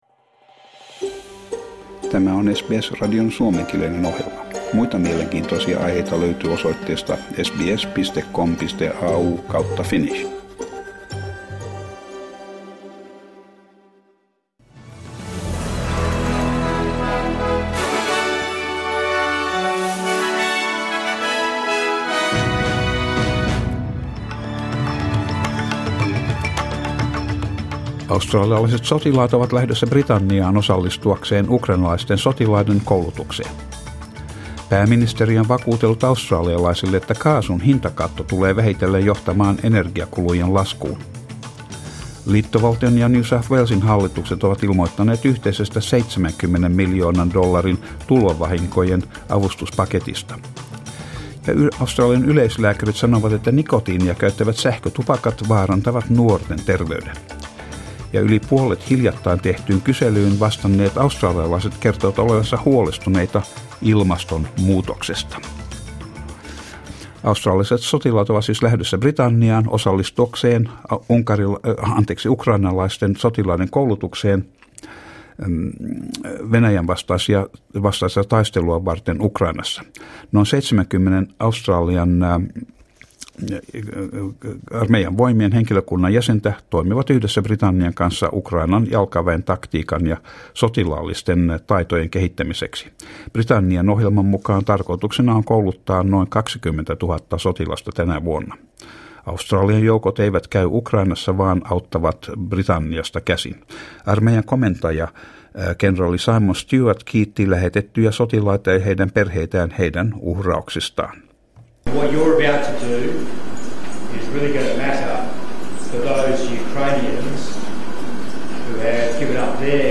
Uutiset 18.1.23